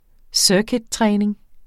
Udtale [ ˈsœːkid- ]